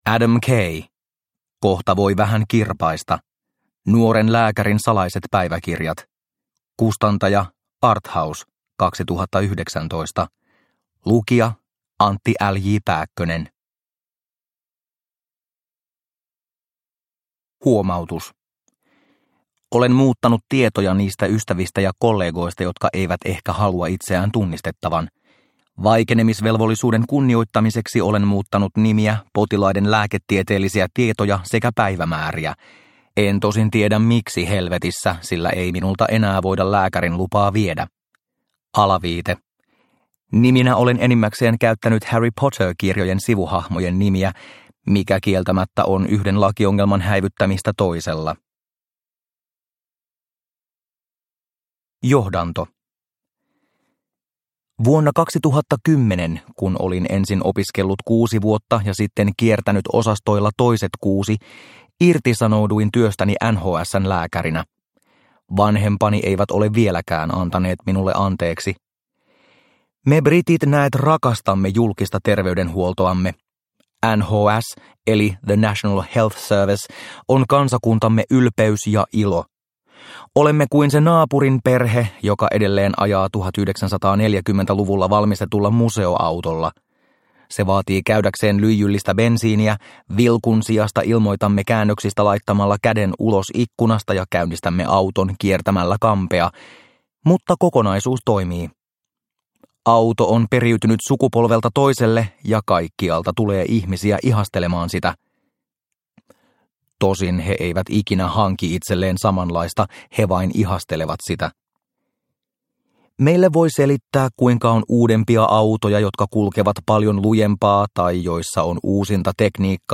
Uppläsare: Antti L.J. Pääkkönen
Ljudbok